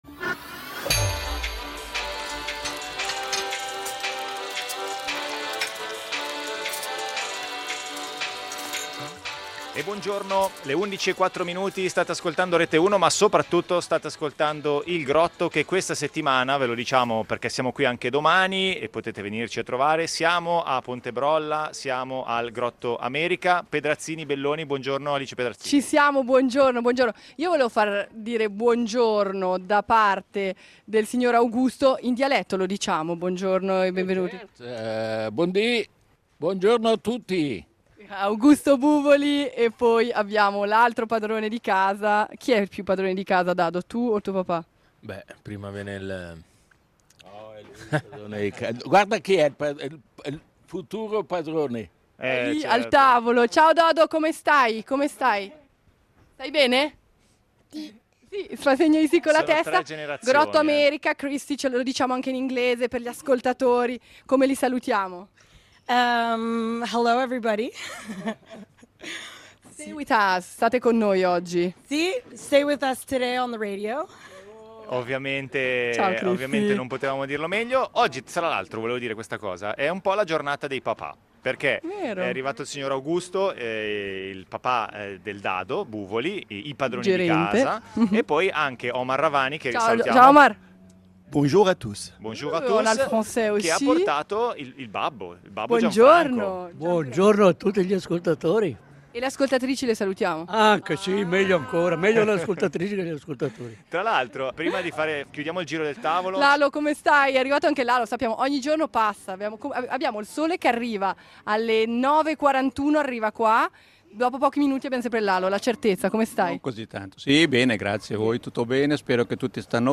Il Grotto al Grotto America a Ponte Brolla